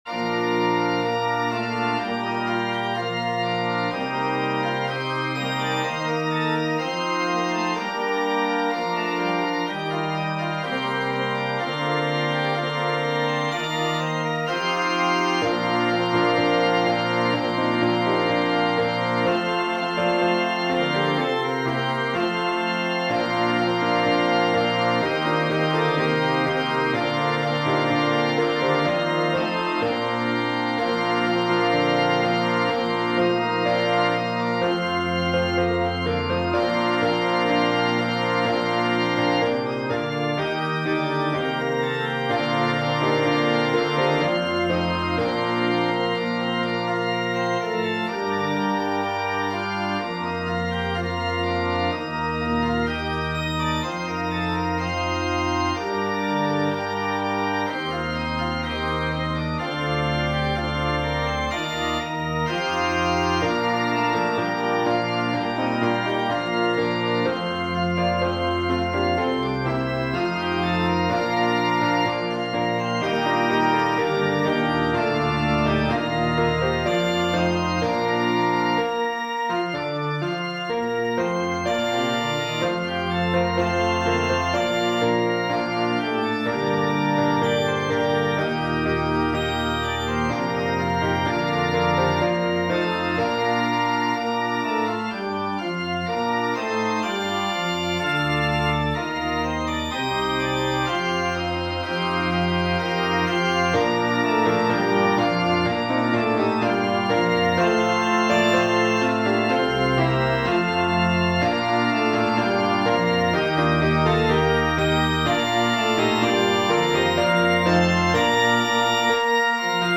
with piano, organ, and congregation
SATB , Organ/Organ Accompaniment